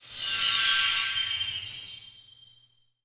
SFX法术魔法光效音效下载
SFX音效